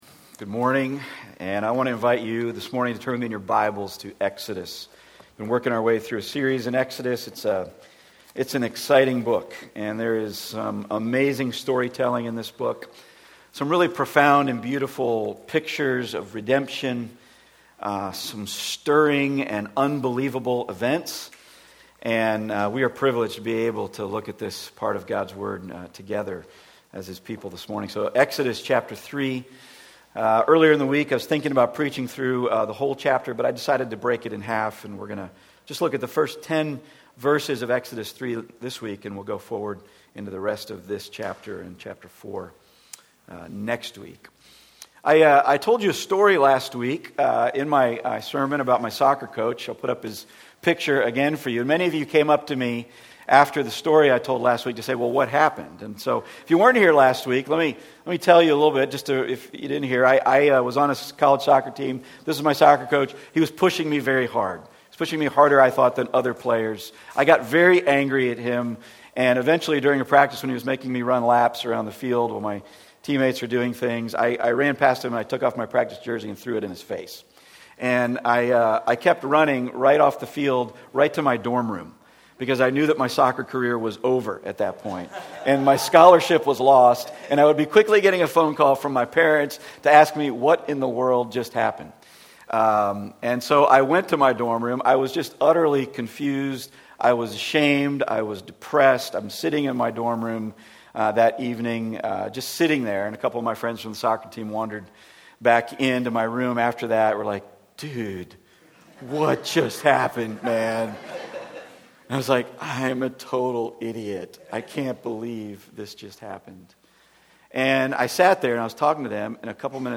Passage: Exodus 3:1-22 Service Type: Weekly Sunday